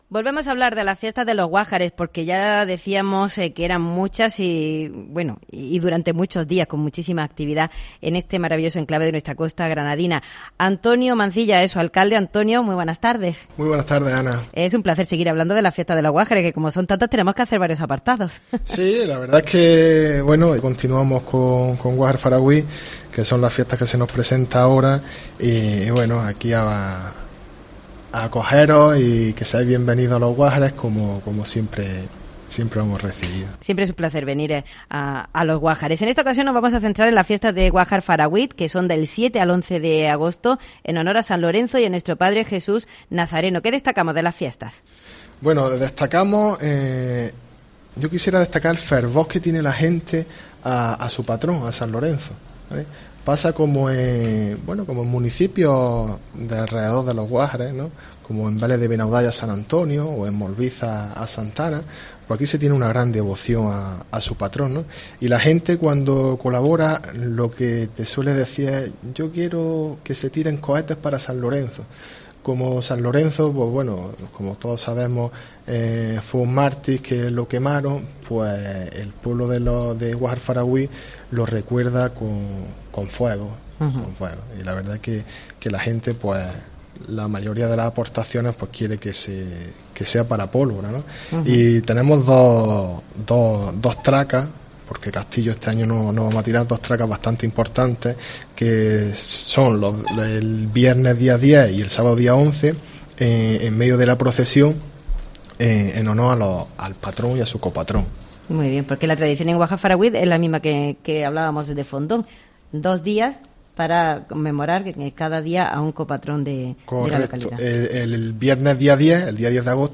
Antonio Mancilla, alcalde de los Guájares nos comenta las fiestas de Guajar Faragüit del 7 al 11-8